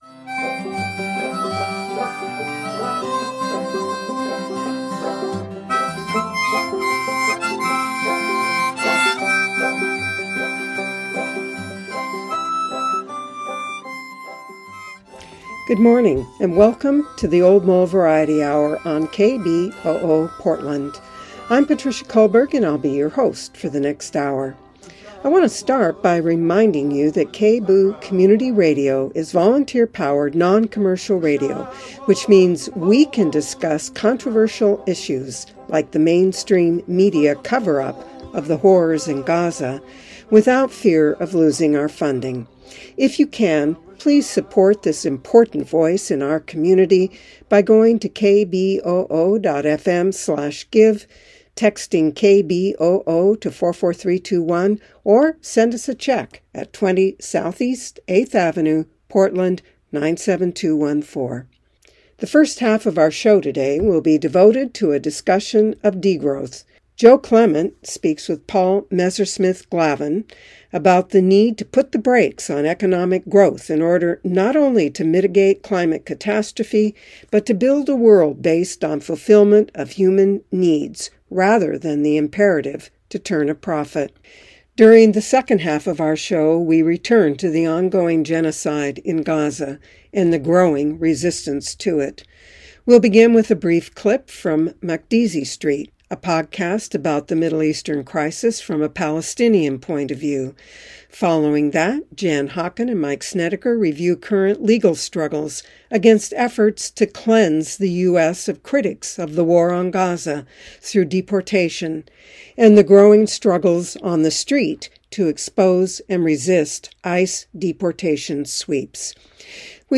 Views, Reviews, and Interviews from a Socialist-Feminist, Anti-racist, Anti-colonial and LGBTQ-positive Perspective